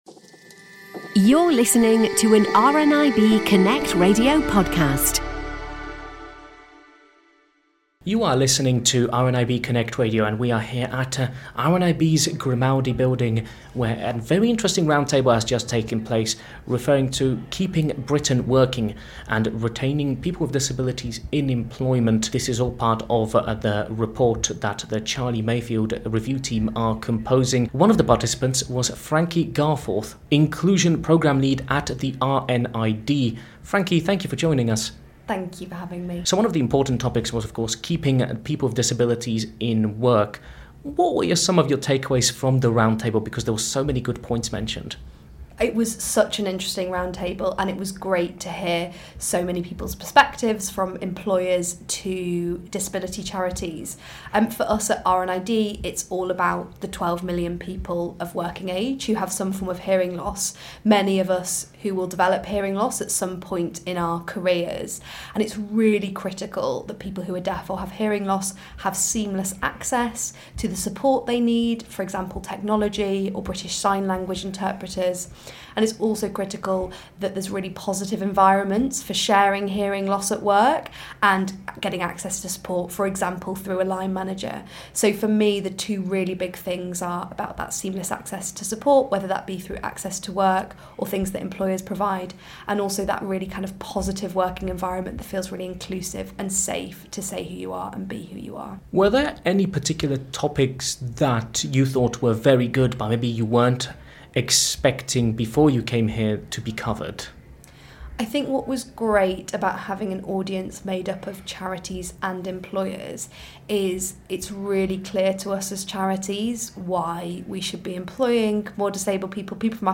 Following the event, he spoke to some of the guests, including the RNID.